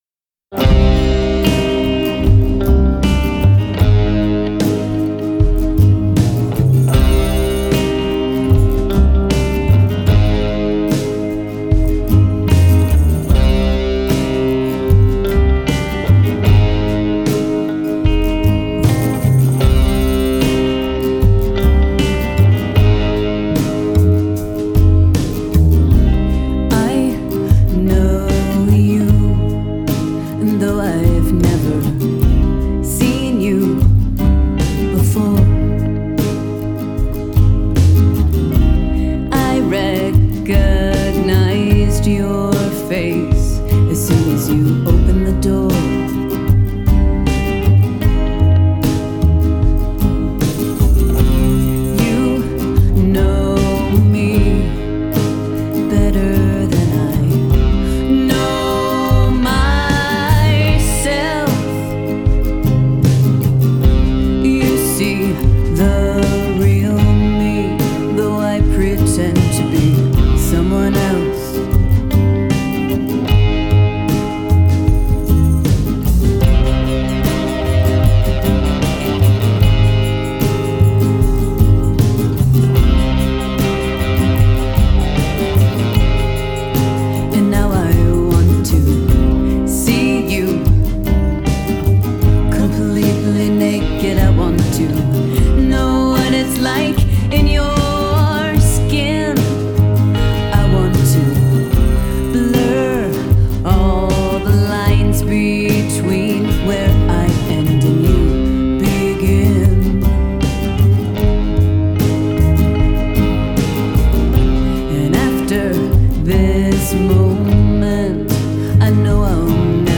Genre: Pop, Rock